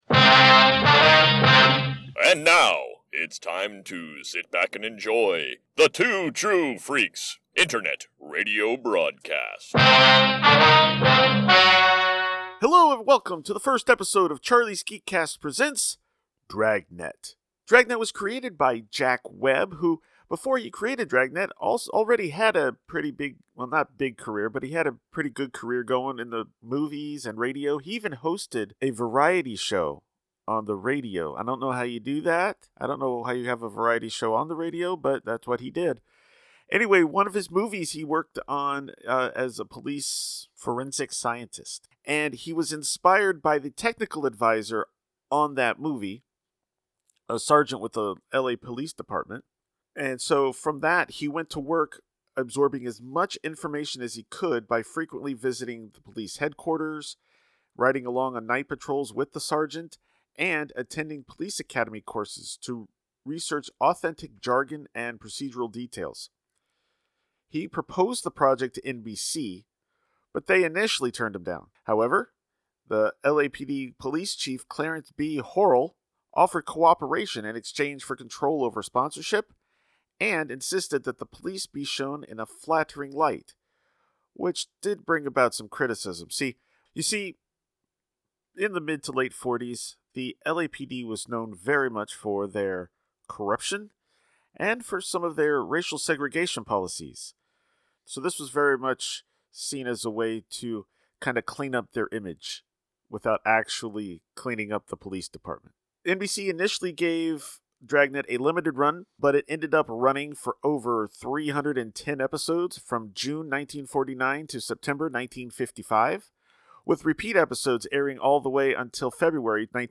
Presenting the first in a new series bringing some of the best radio shows of yesteryear right to your ears. First up, Dragnet episode 02: “The Nickel Plated Gun.”